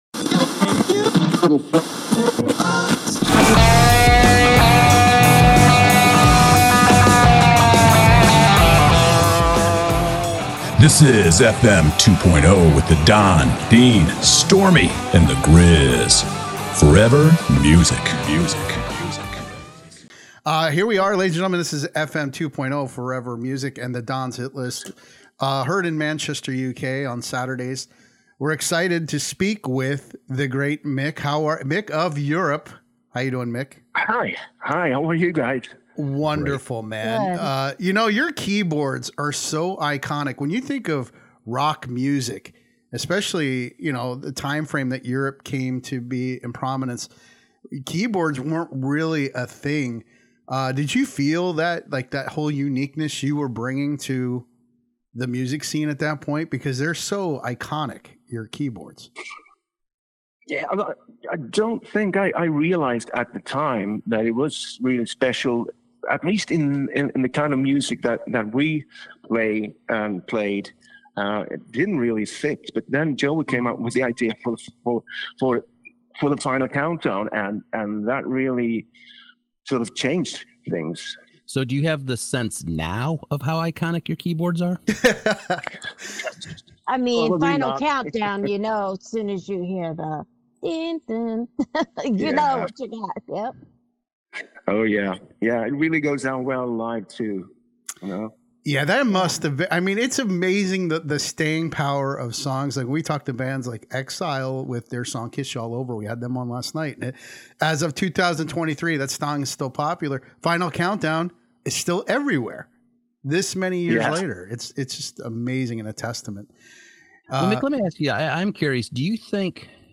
Mic Michaeli: Keyboardist Of The Influential Rock Band EUROPE Celebrates 40 Years Of Music w FM 2.0 Conversations